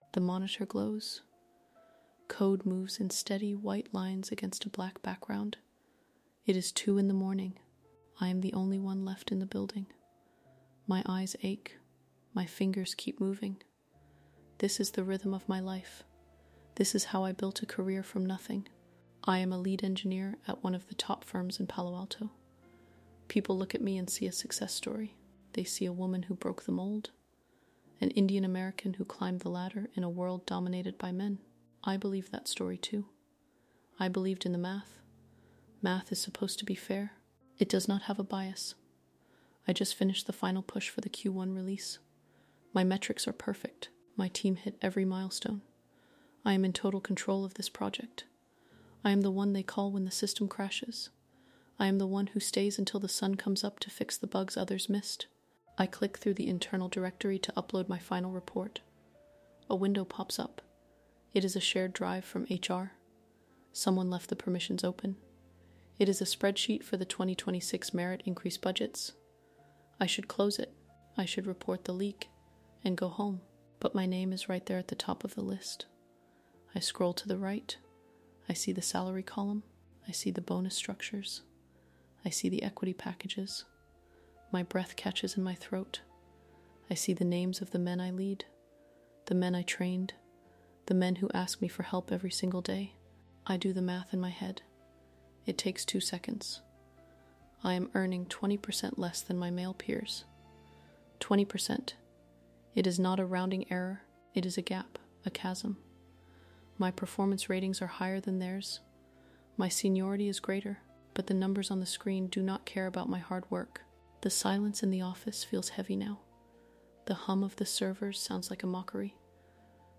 This first-person narrative dives into the psychological toll of discovering that colleagues with less experience and lower seniority are out-earning their mentors.